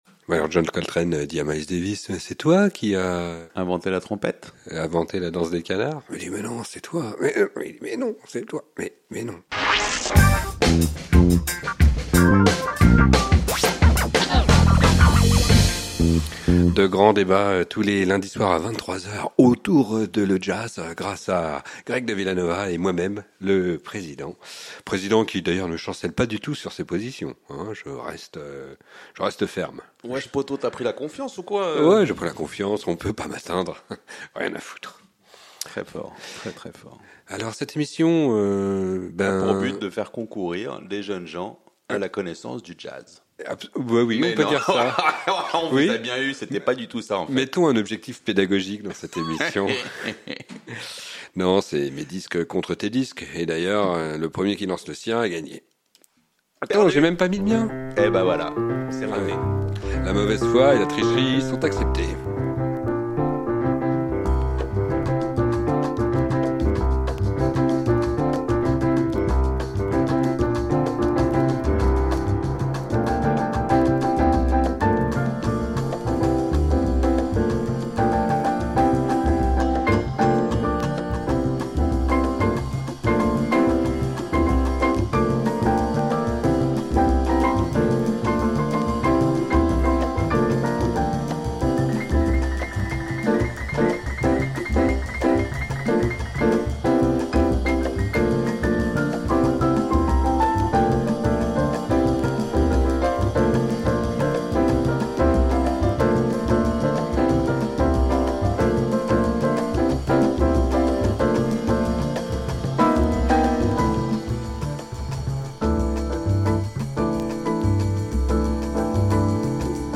Classique & jazz